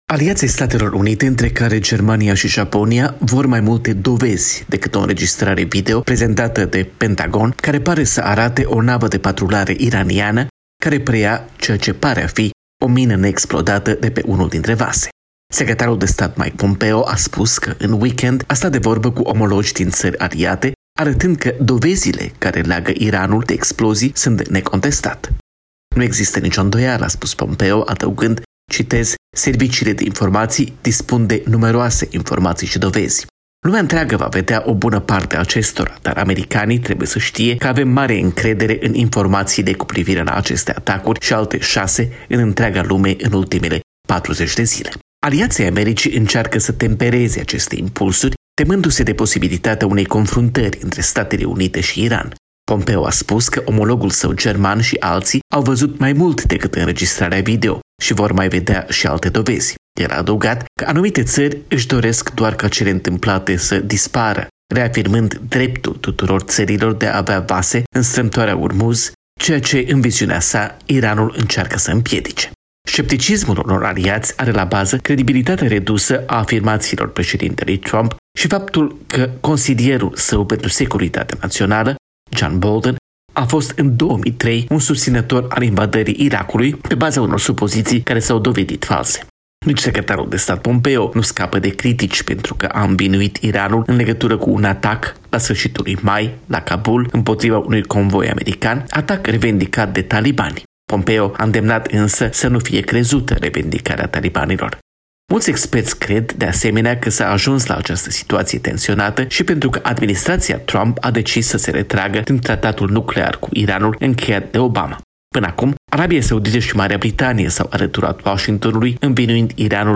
Corespondența zilei de la Washington